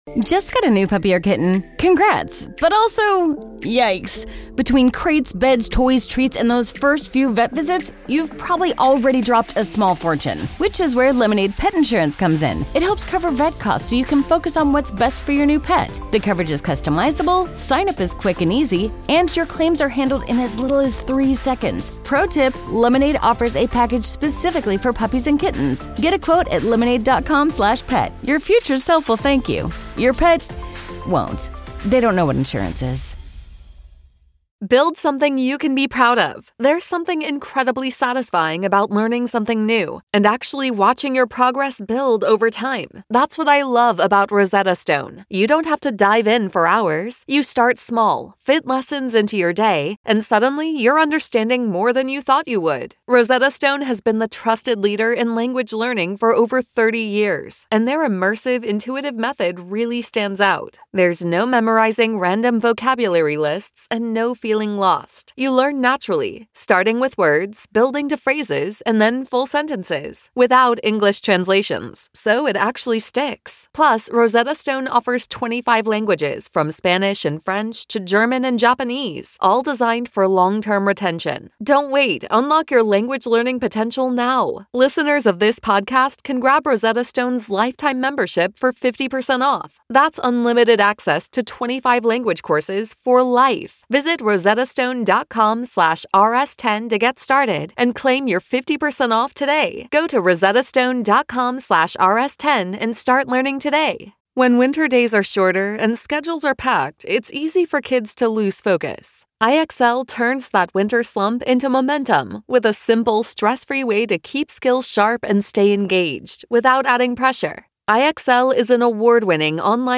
When will Jayson Tatum return to the court? Callers join to share their thoughts. The guys try to fix the NBA All-Star Game